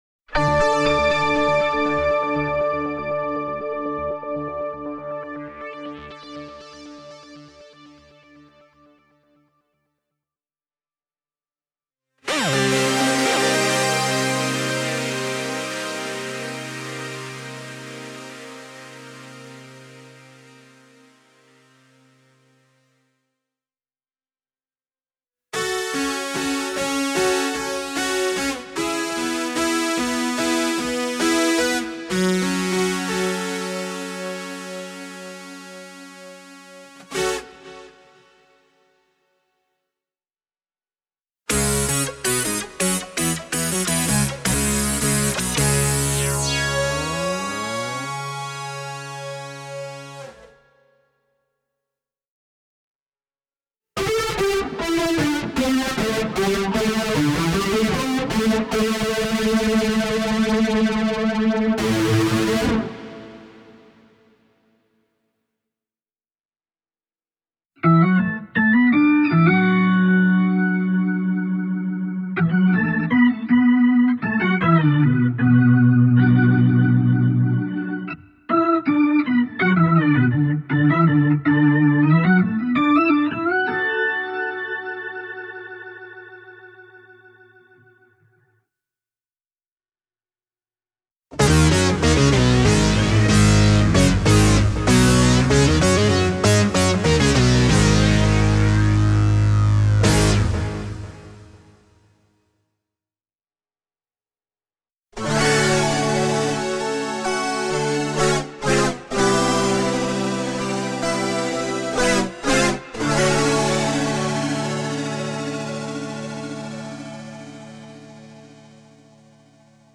This clip gives you some idea of the types of patches included in the factory settings: